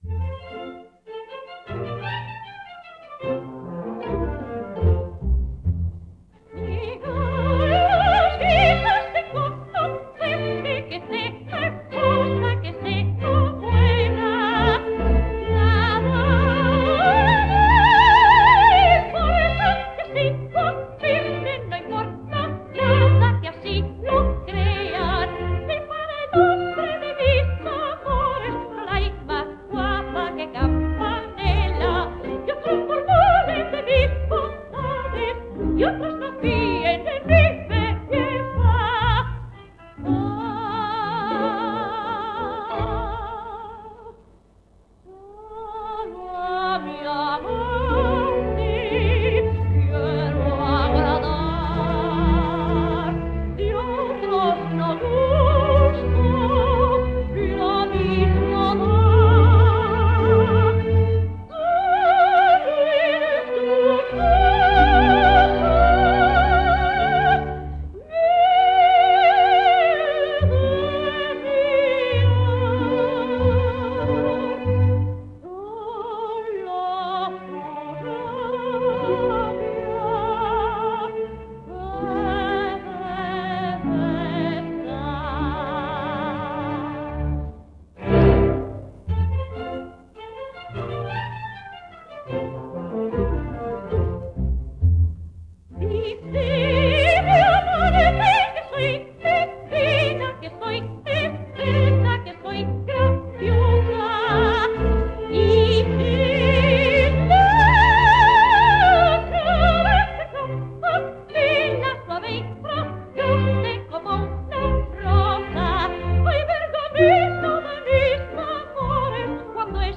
orquesta